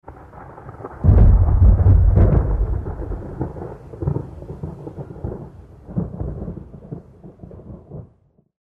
thunder9.mp3